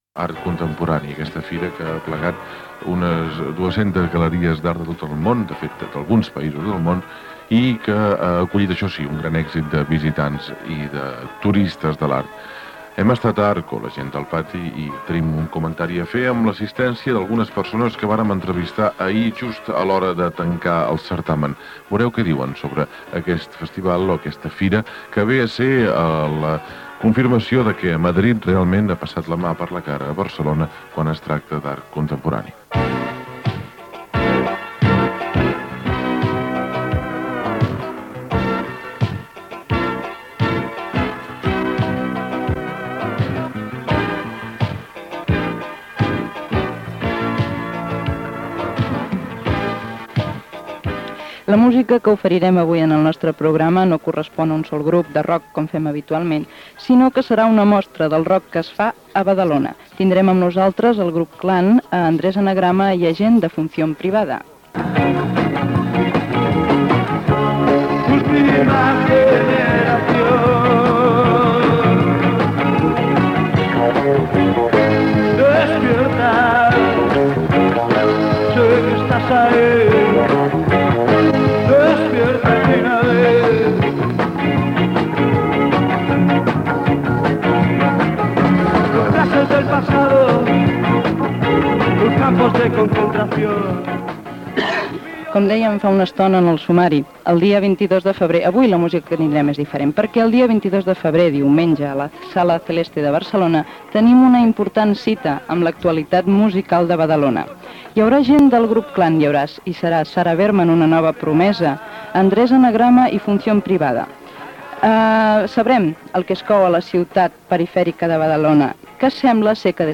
Entrevista a membres del grup Clan i Función Privada